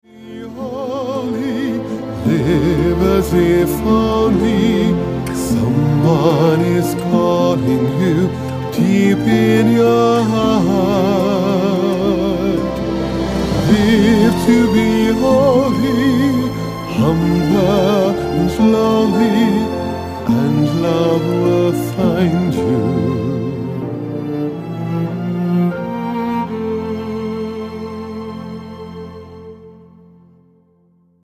Voicing: Two-part mixed